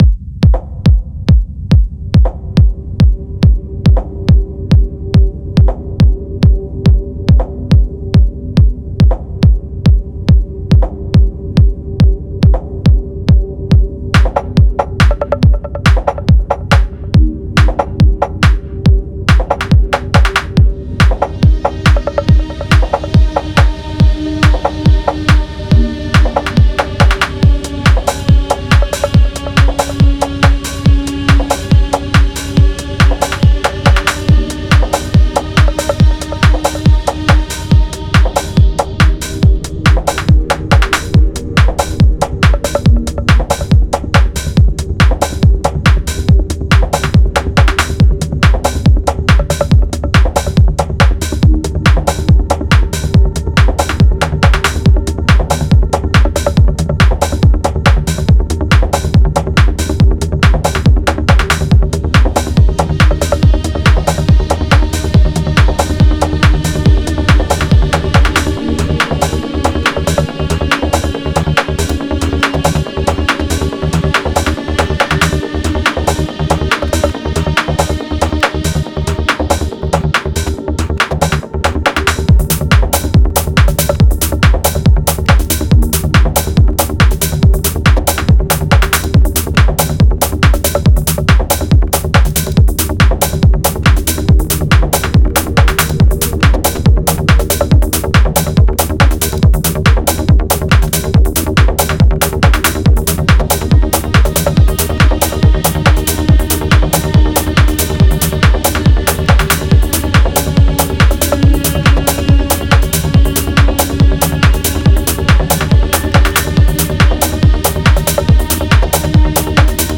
Techno Contest - Acid/Rave Edition